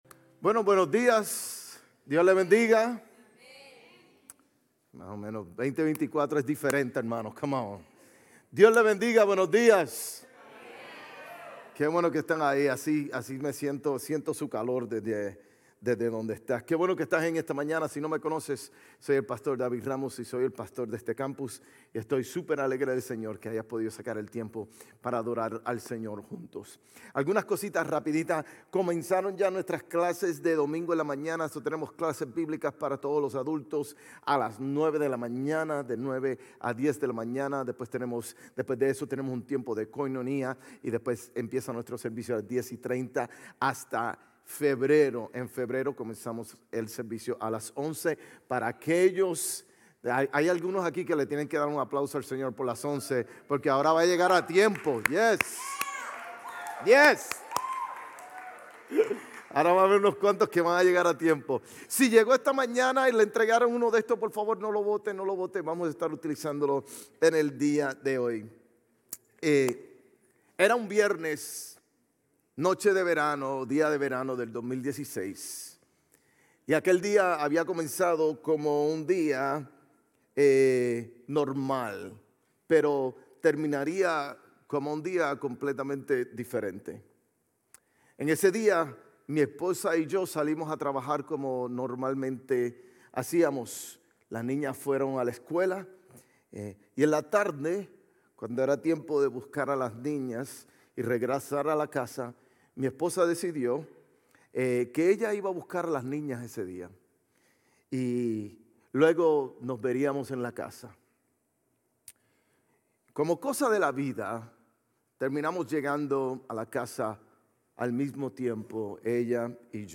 Sermones Grace Español Resoluciones con Resultados Eternos Jan 08 2024 | 00:35:54 1x 00:00 / 00:35:54 Subscribe Share RSS Feed Share Link Embed